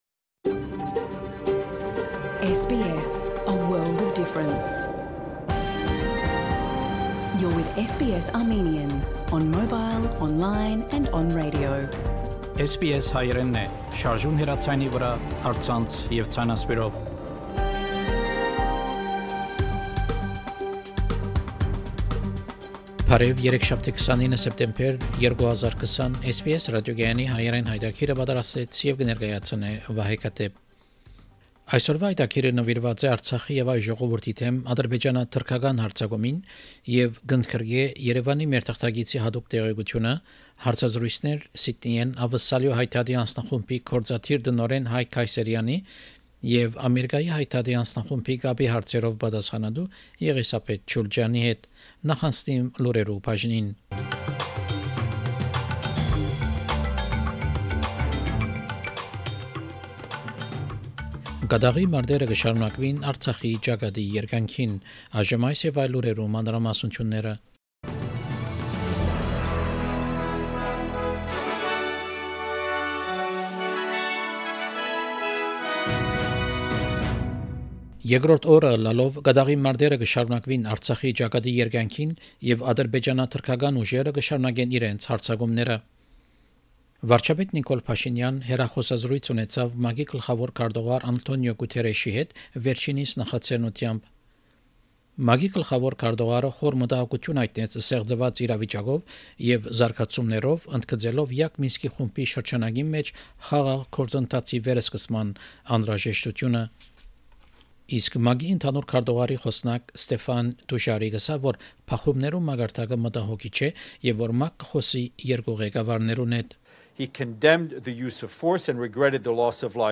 SBS Armenian news bulletin – 29 September 2020
SBS Armenian news bulletin from September 29, 2020 program.